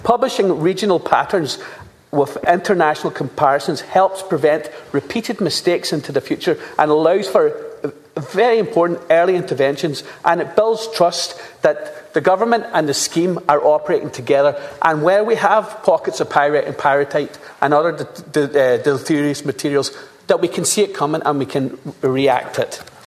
Meanwhile, 100% Redress Deputy Charles Ward called for the publication of trends and data relating to defective concrete, saying that identifying patterns at an early stage would allow for earlier responses………….